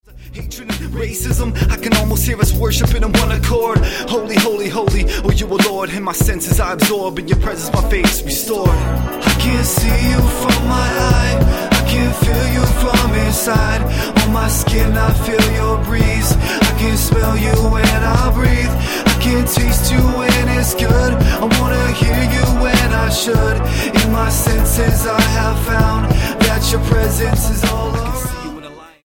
The Canadian rapper
Style: Hip-Hop